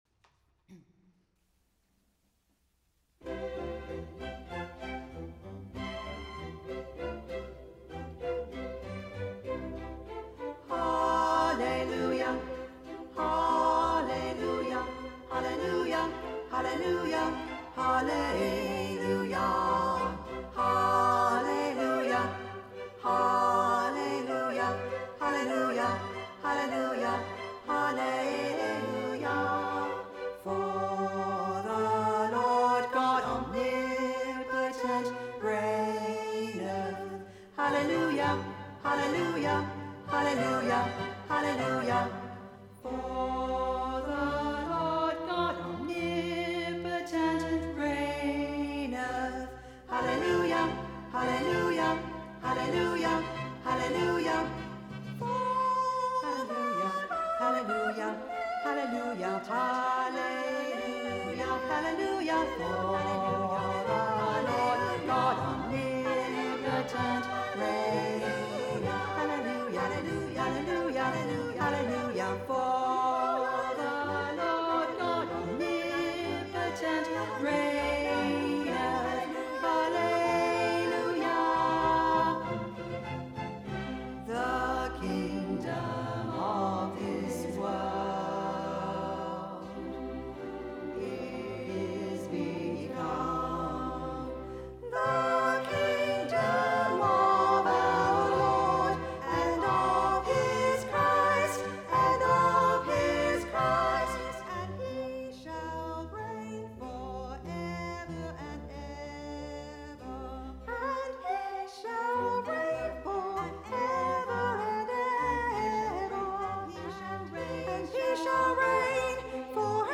Tenor
Hallelujah_Chorus_Tenor_focus.mp3